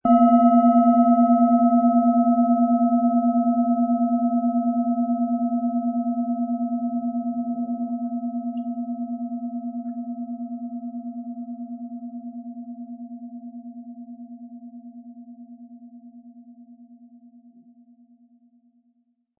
Planetenton
Unter dem Artikel-Bild finden Sie den Original-Klang dieser Schale im Audio-Player - Jetzt reinhören.
Durch die traditionsreiche Herstellung hat die Schale stattdessen diesen einmaligen Ton und das besondere, bewegende Schwingen der traditionellen Handarbeit.
MaterialBronze